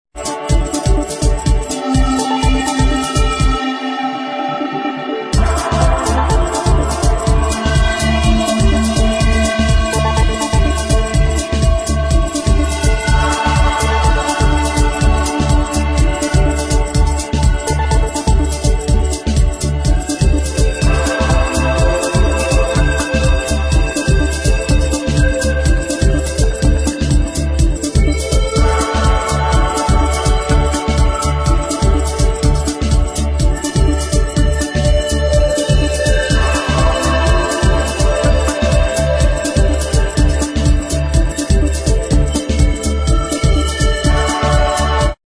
[ HOUSE ]
Instrumental